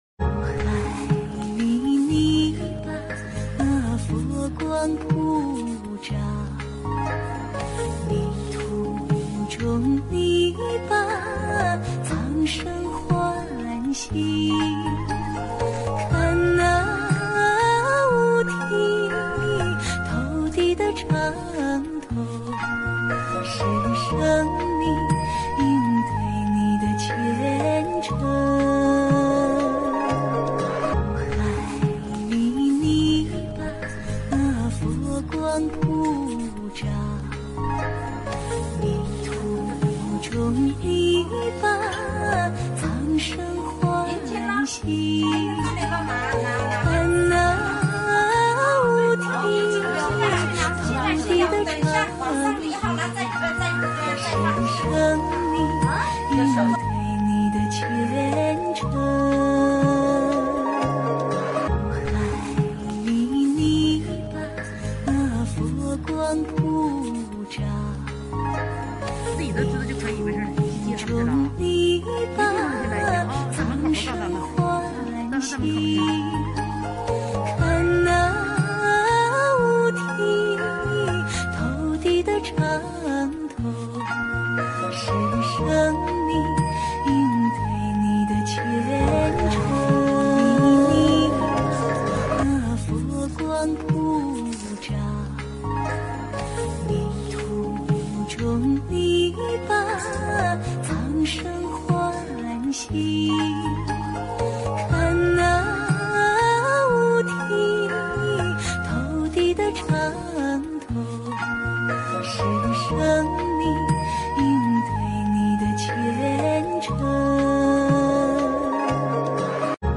音频：西班牙萨拉高萨心灵法门共修会活动视频！